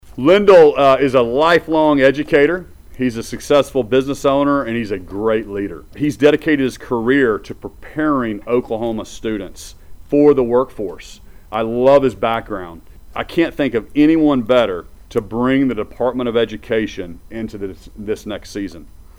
An air of excitement filled the library at Eisenhower International School in Tulsa as Gov. Kevin Stitt formally announced Lindel Fields, the former superintendent of Tri County Tech in Bartlesville, is now the Oklahoma State Superintendent of Public Instruction.